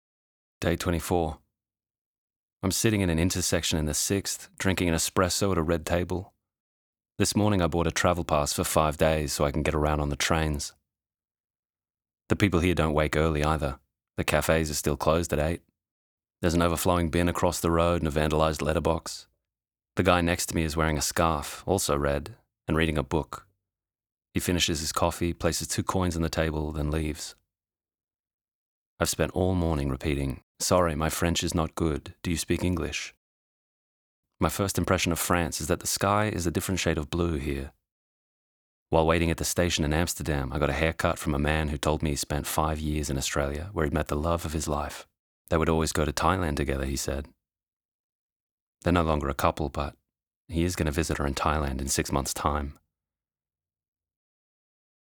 Audiobook_Australian Accent